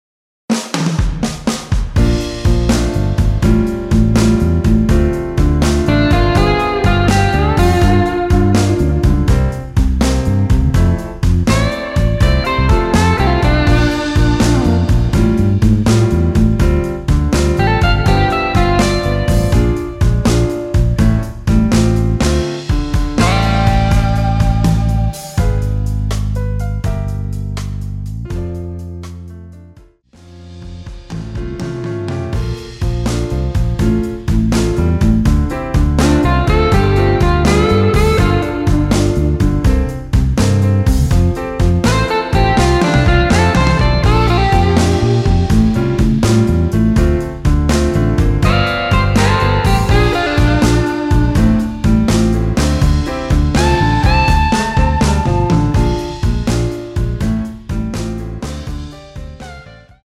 Dm
◈ 곡명 옆 (-1)은 반음 내림, (+1)은 반음 올림 입니다.
앞부분30초, 뒷부분30초씩 편집해서 올려 드리고 있습니다.
중간에 음이 끈어지고 다시 나오는 이유는